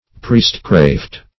Priestcraft \Priest"craft`\, n.